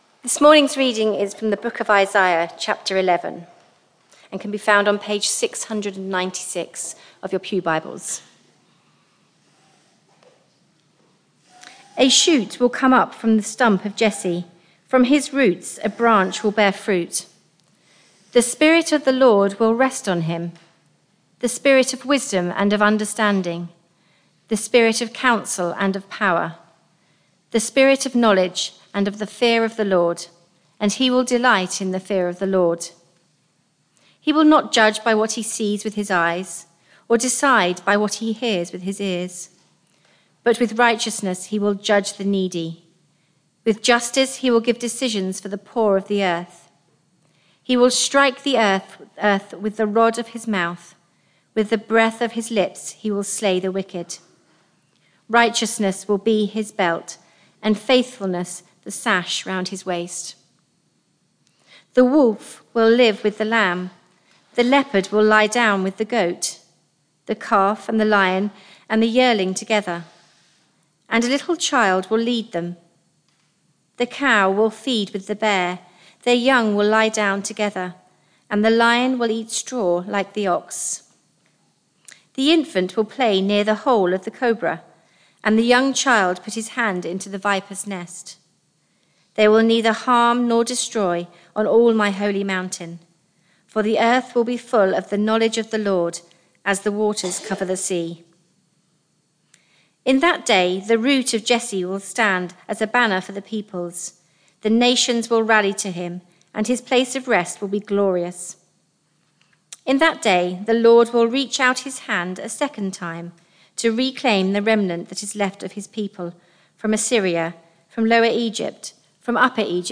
Barkham Morning Service
Reading and Sermon